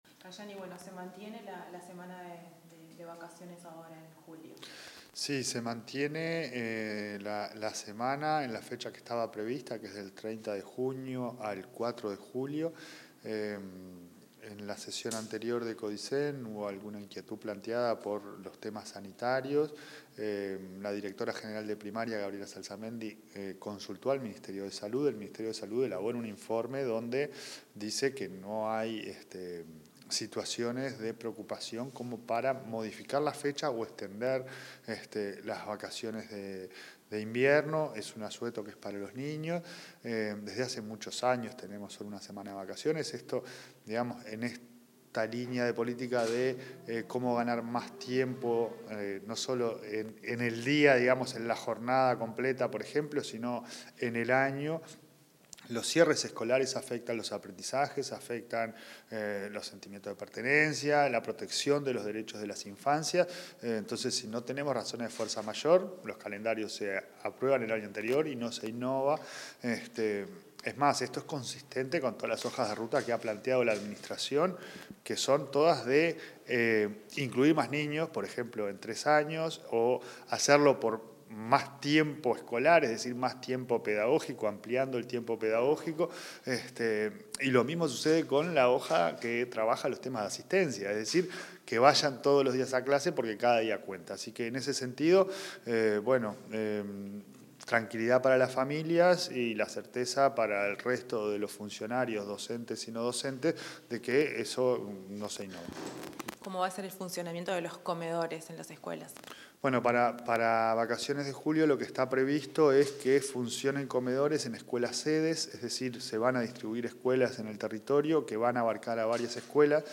Declaraciones del presidente de la ANEP, Pablo Caggiani